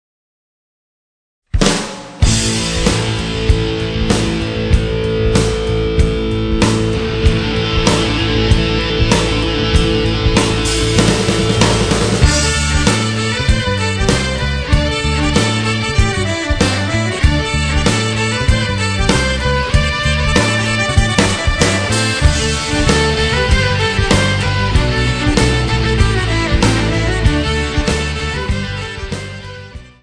Enregistrement live+Mixage+Mastering.